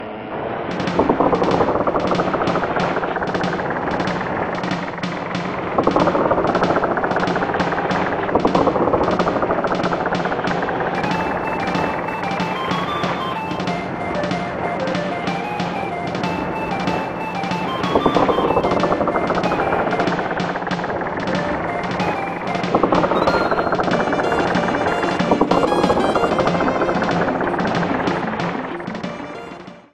Edited Clipped to 30 seconds and applied fade-out.